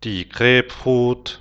Grepik (627x640)grep die Grapefruit [gre:pfru:t]
die-Grapefruit.wav